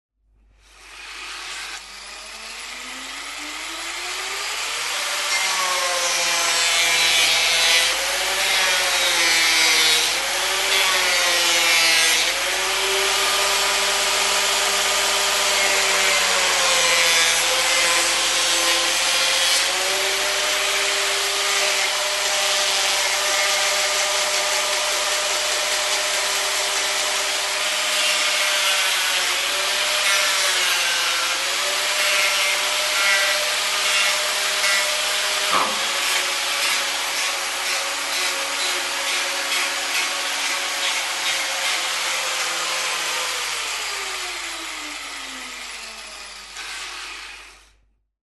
На этой странице собраны различные звуки болгарки: от коротких резких скрежетов до продолжительной работы инструмента.
Осторожно: громко!
Шум болгарки по дереву, звуки столярной мастерской, резка древесины болгаркой